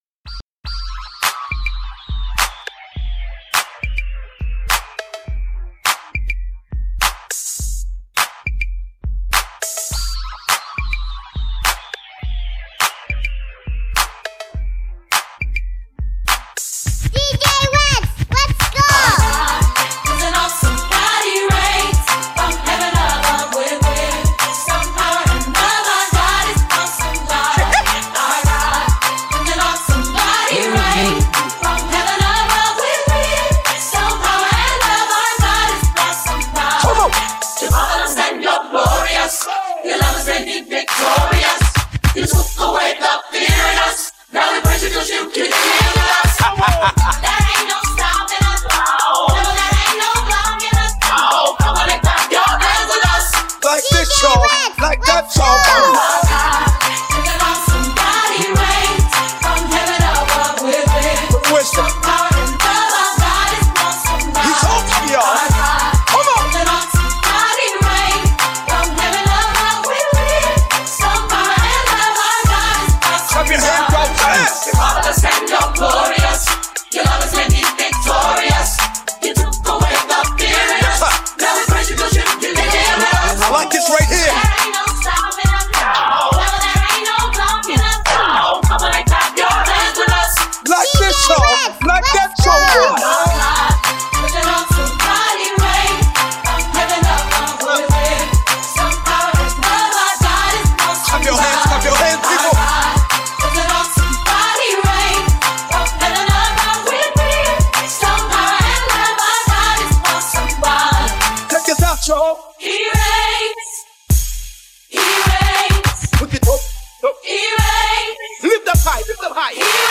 Gospel
HipHop